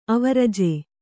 ♪ avaraje